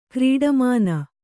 ♪ krīḍamāna